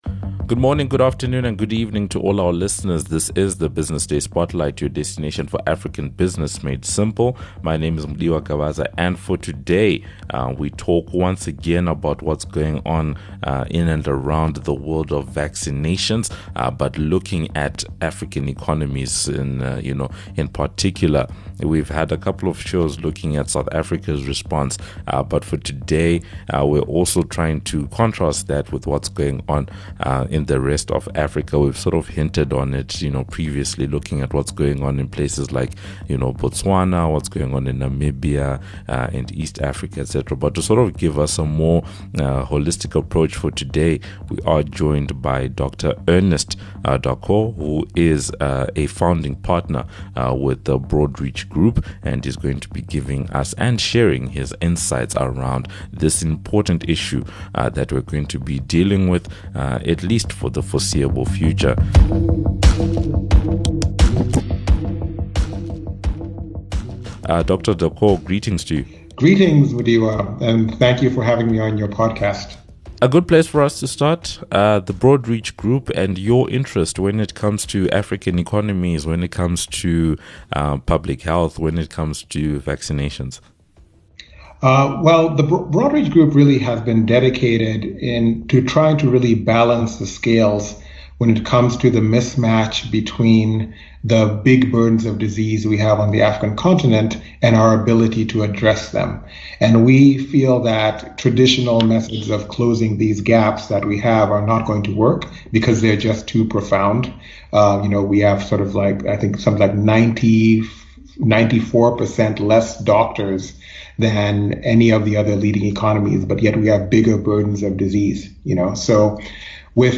The discussion focuses on the rollout of Covid-19 vaccines in Africa; challenges and opportunities around the rollout; the strength of African economies; the importance of regional integration; and how current rollout practices can be improved.